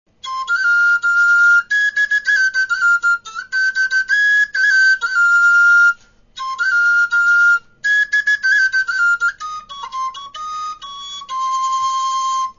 LA FLAUTA MARAGATA
Los ejercicios de esta sección se han realizado con la flauta “Casadina”, cuya nota básica inferior en digitación cerrada (LA) de sonido real es el SI bemol, en la que puede apreciarse una ligera tendencia a dar un poquito baja la tercera mayor (DO sostenido).
Las grabaciones no pretenden mostrar calidad sonora ni interpretativa, y deben tomarse como simples referencias didácticas ilustrativas.
Modo de MI cromatizado (3º #, “gama española”)
Tónica en SI